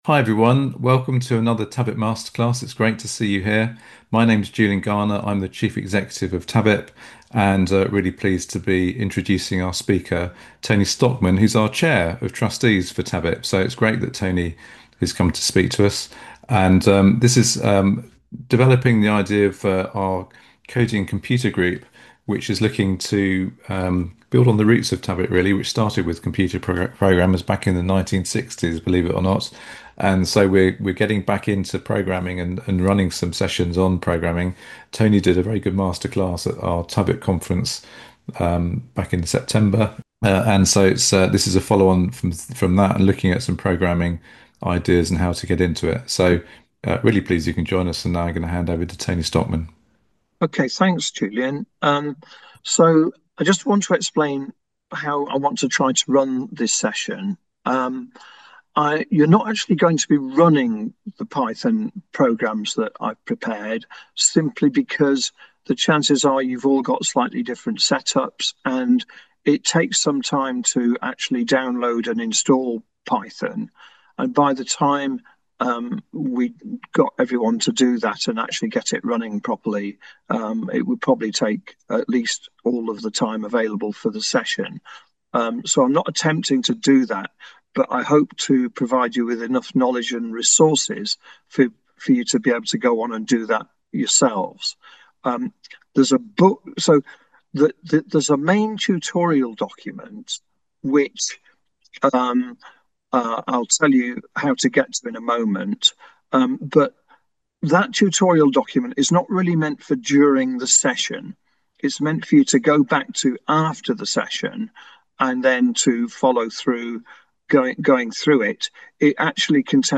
In this talk, we are going to look at programming language statements in some detail.